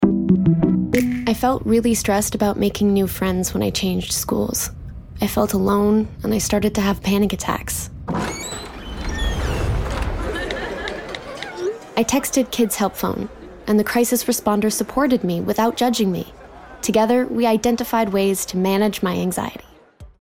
Commercial (Desjardins 1) - EN